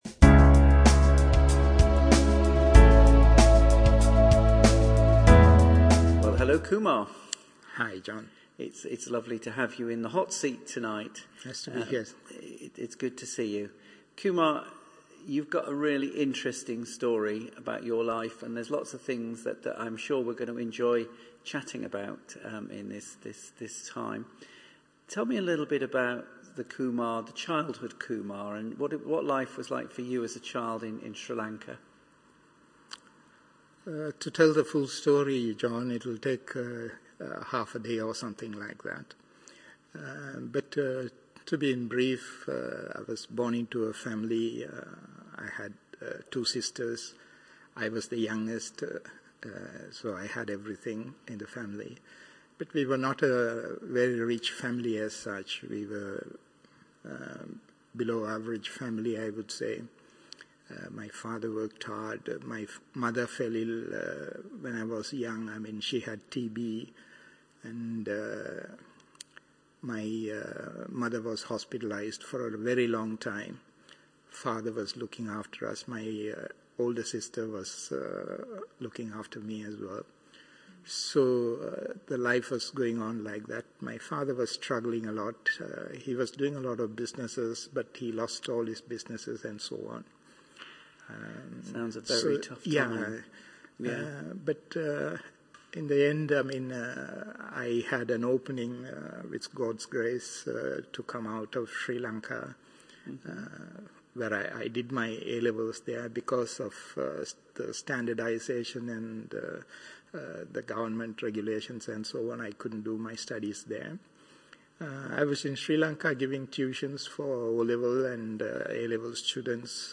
Video Interview Podcast (Audio Only)